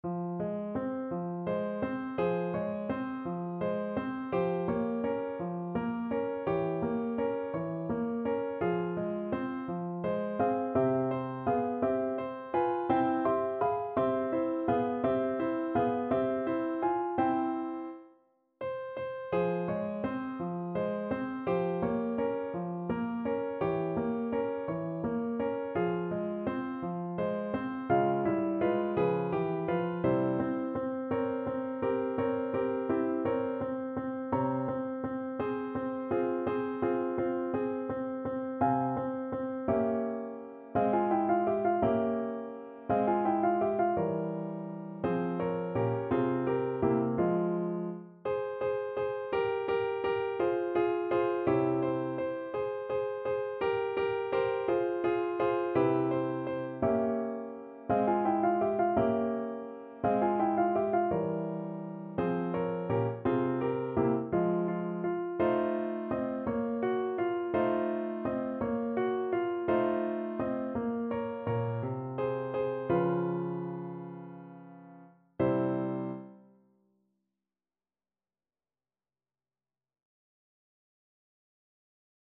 . = 56 Andante
6/8 (View more 6/8 Music)
Classical (View more Classical Soprano Voice Music)